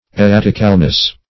-- Er*rat"ic*al*ness , n. [1913 Webster]